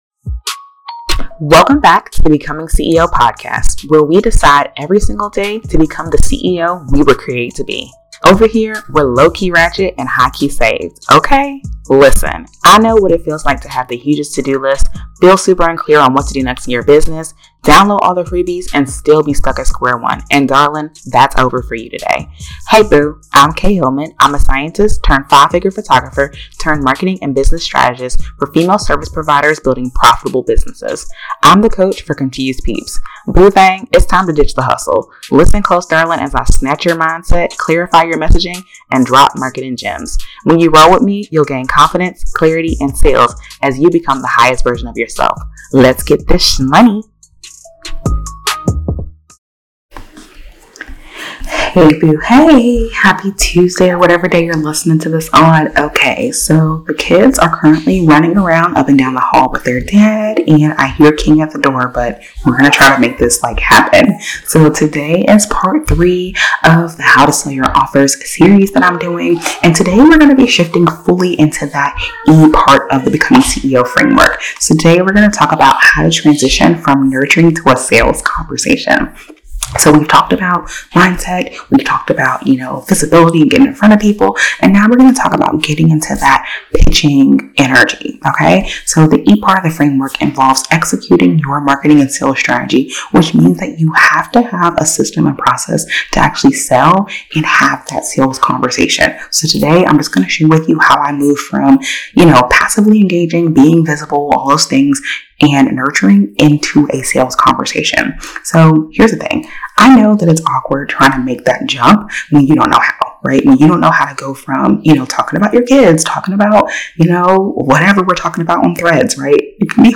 We have a crying baby at the end and I didn't have time to re-record!